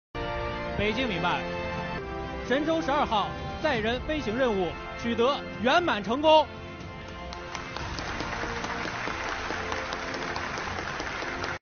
虽然调度的声音听起来很平静